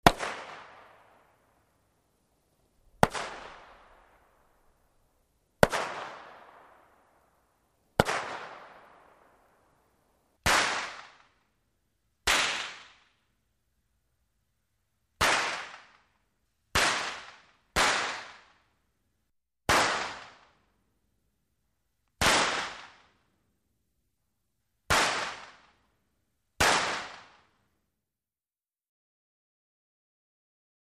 Firecracker Single Explosions, Medium Interior Perspective.